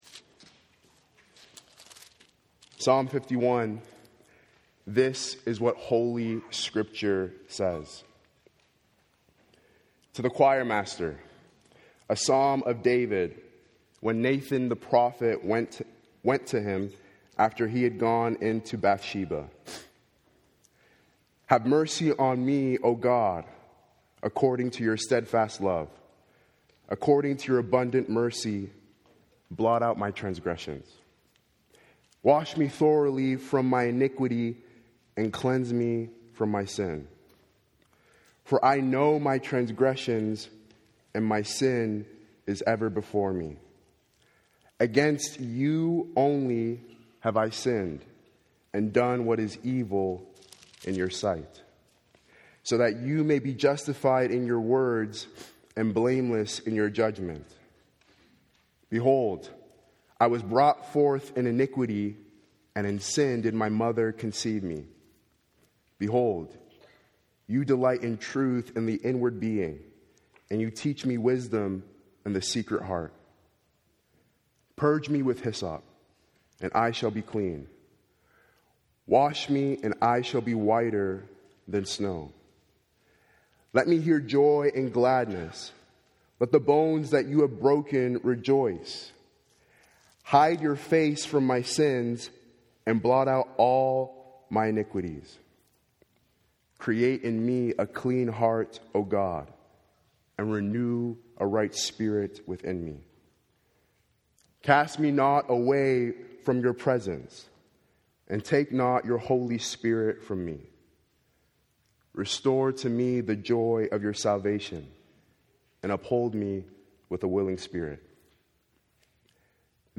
Sermons
Weekly sermons from Grace Fellowship Church of West Toronto